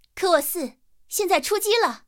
KV-4出击语音.OGG